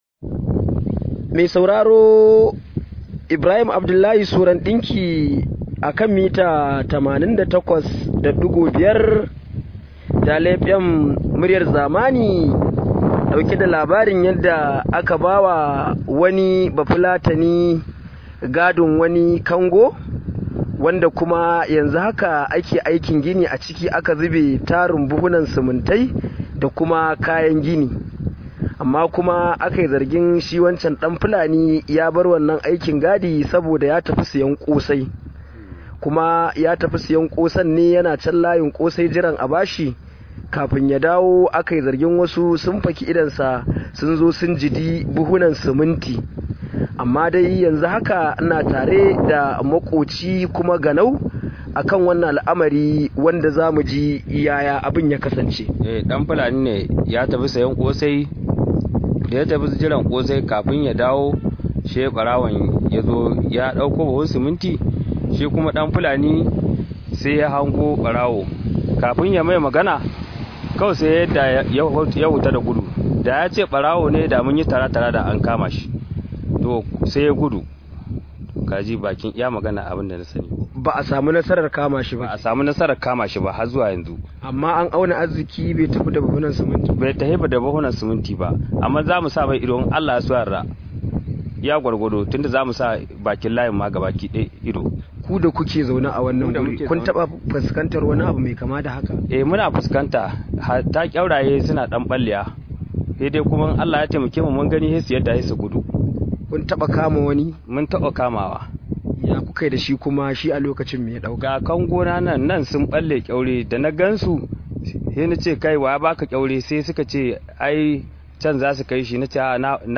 Rahoto: Matashi ya yi yunkurin satar buhun Siminti a Kango